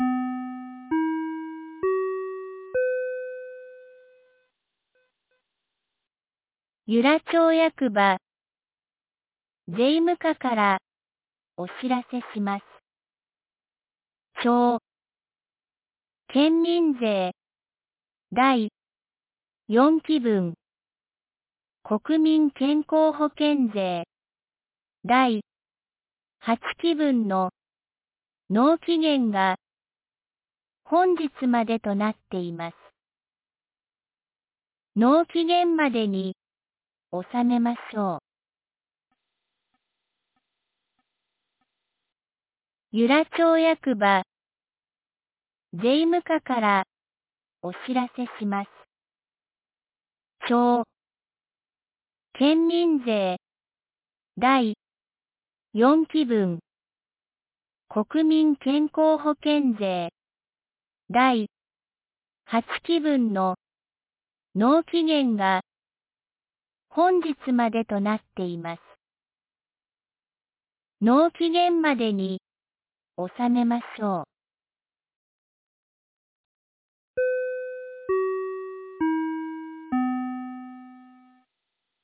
2026年02月02日 07時51分に、由良町から全地区へ放送がありました。